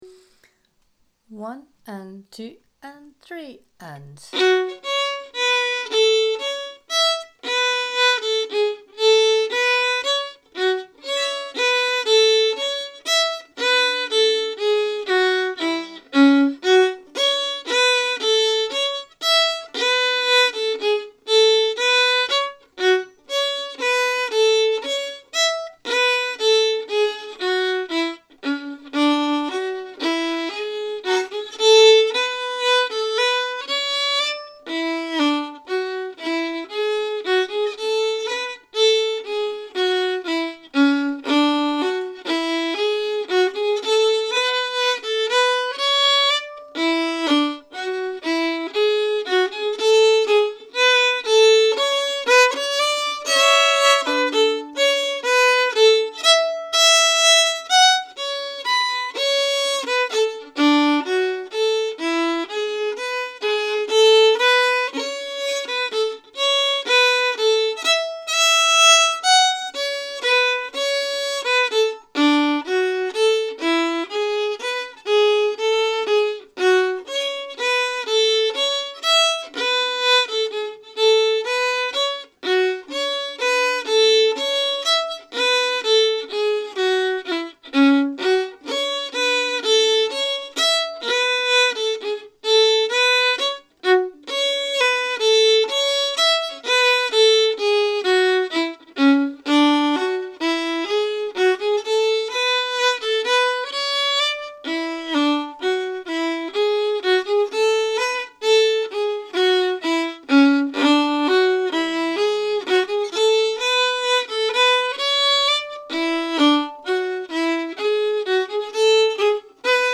Key: F#m
Form: 3/2 hornpipe
Slow melody for learning